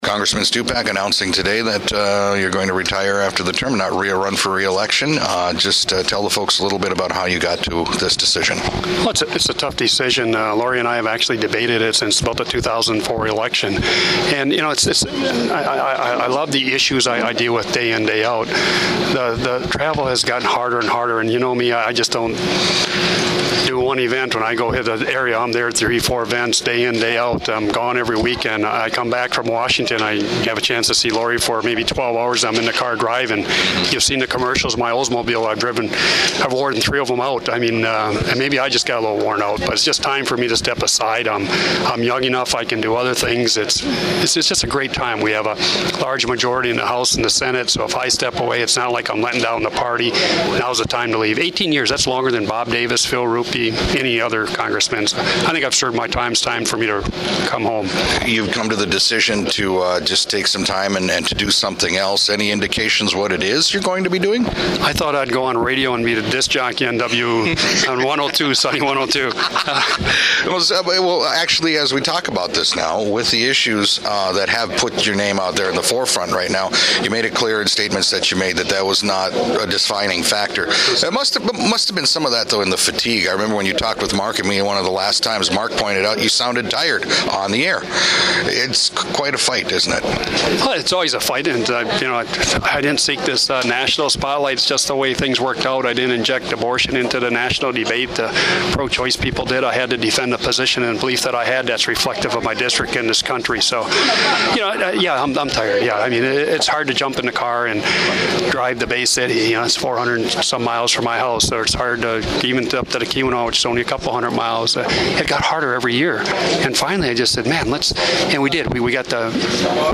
Speaking at a press conference at the Superior Dome, and citing a 30-year career in public service including representing the 1st District in Congress for 18 years, Stupak announced today that he will not seek re-election in November.